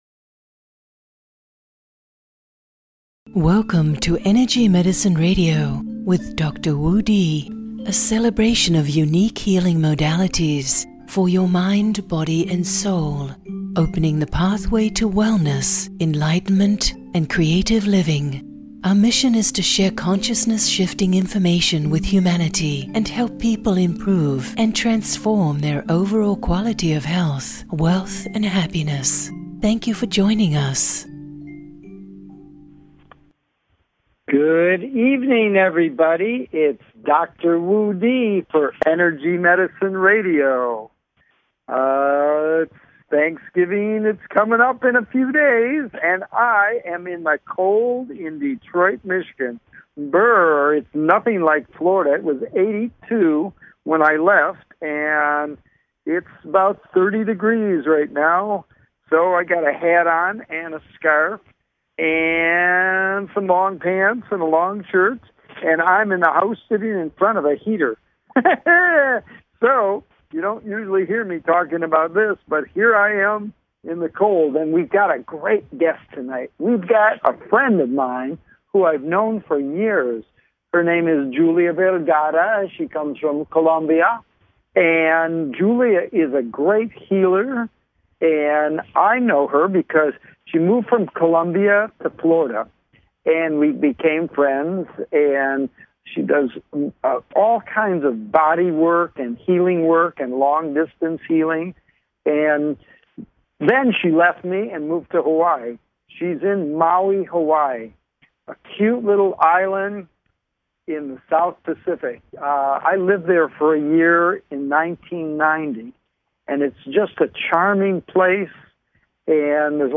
Headlined Show, Energy Medicine Radio November 25, 2014